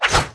attack_0.wav